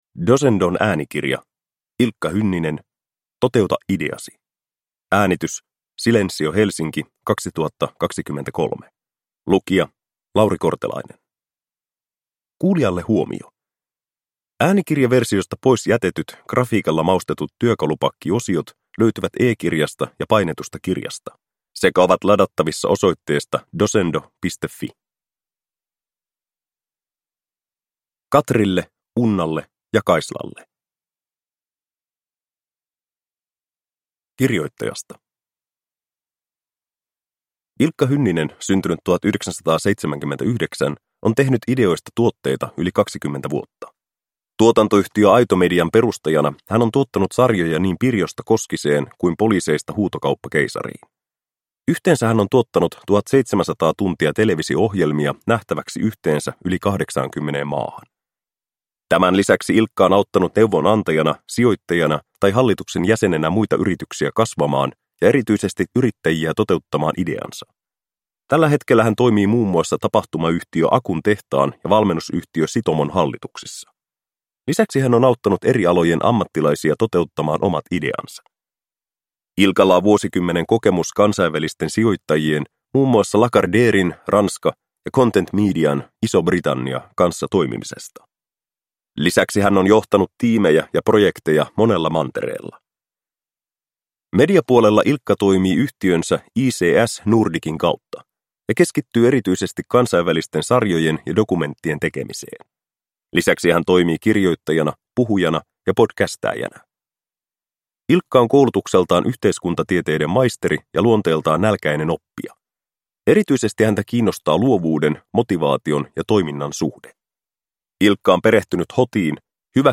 Toteuta ideasi – Ljudbok – Laddas ner